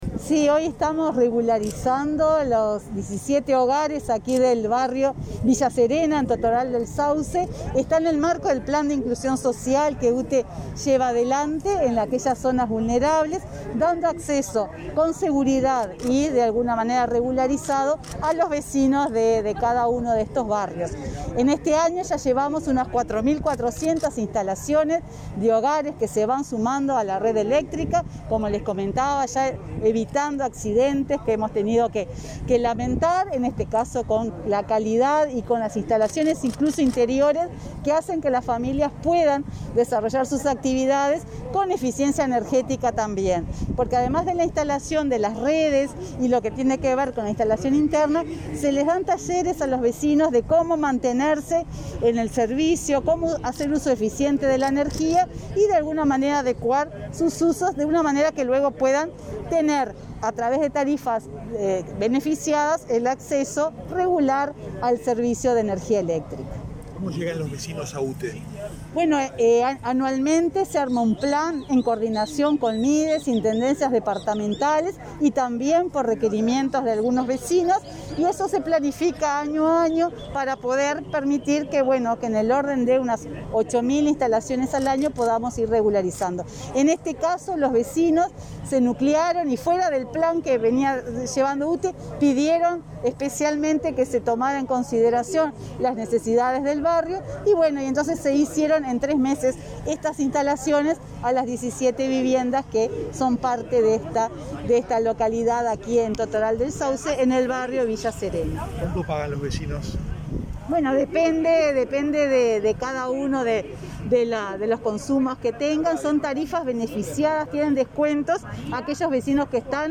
Declaraciones a la prensa de la presidenta de UTE, Silvia Emaldi
Este martes 24, la presidenta de UTE, Silvia Emaldi, dialogó con la prensa, luego de participar del acto de regularización de un conjunto de viviendas